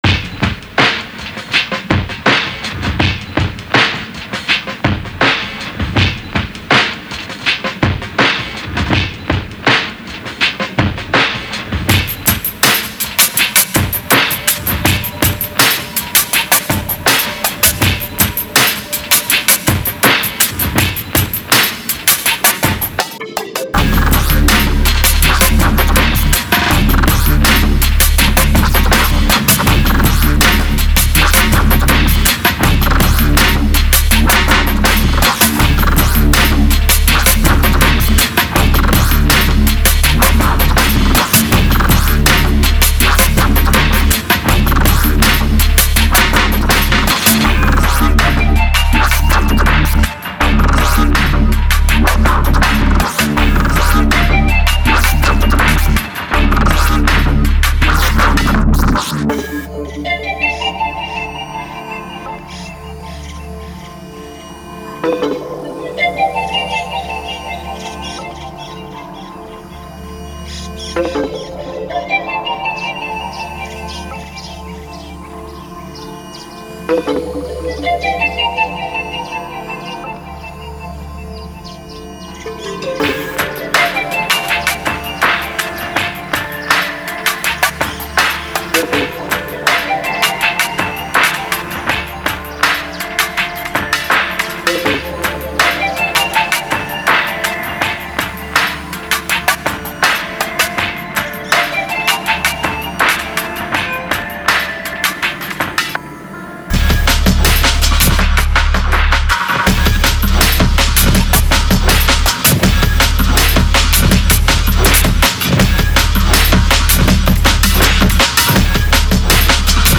DRUMFUNK